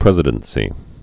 (prĕzĭ-dən-sē, -dĕn-)